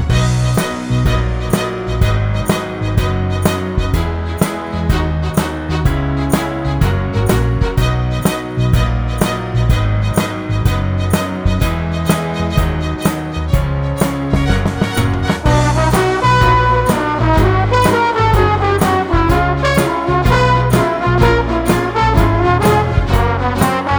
Minus Piano